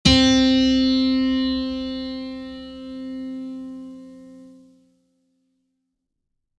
HardAndToughPiano
c3.mp3